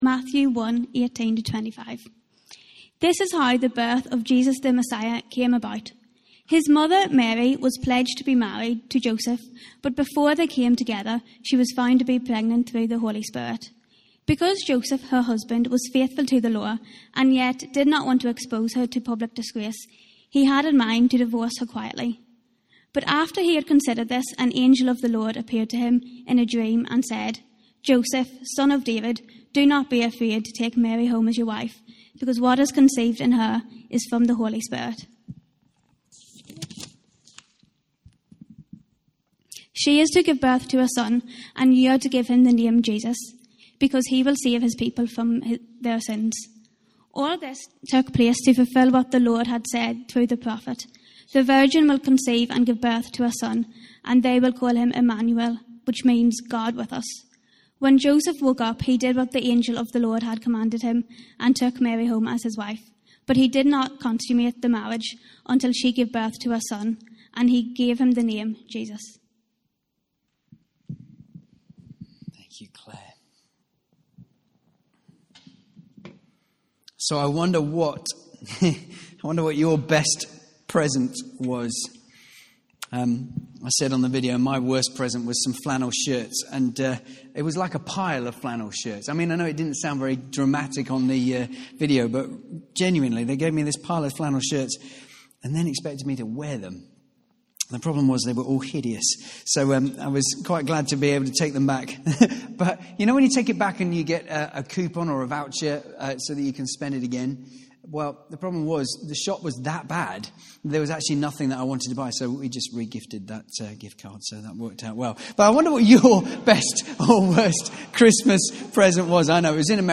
Carols by Candlelight - Sunday 11th December 2016